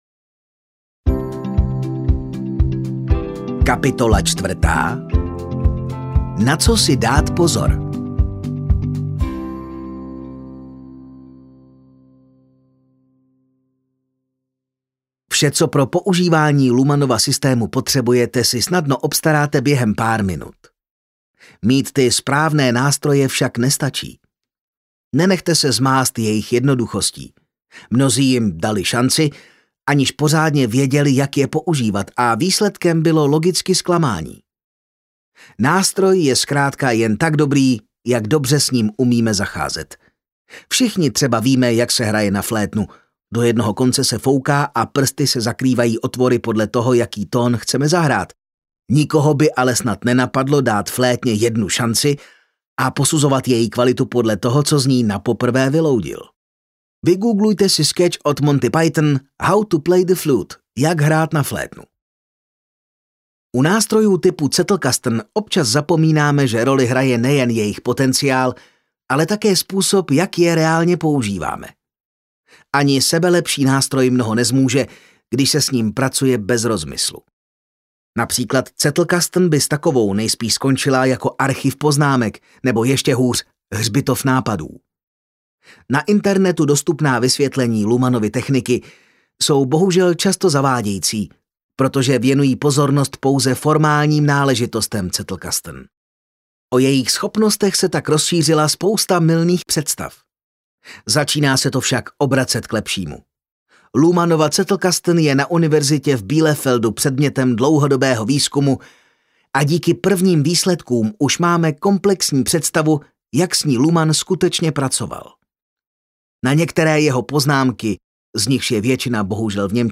Jak si dělat chytré poznámky audiokniha
Ukázka z knihy